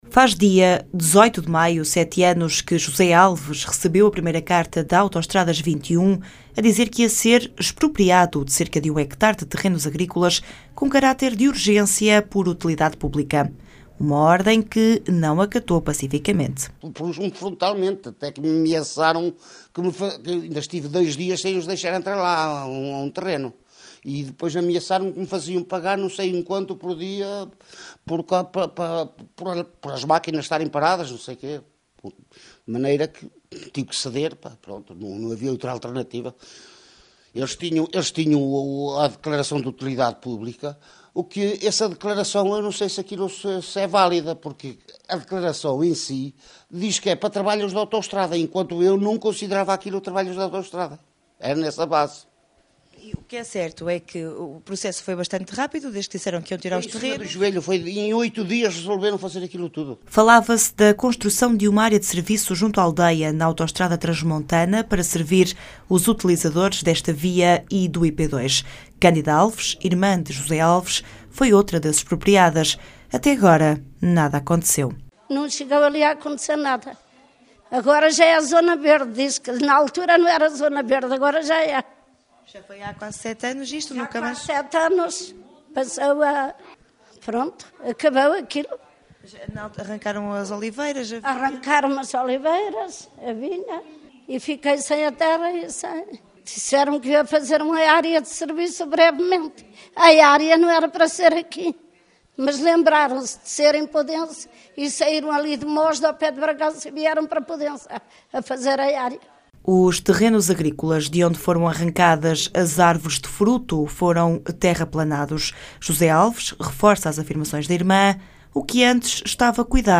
rd_-reportagem-nada-de-area-de-serviço.mp3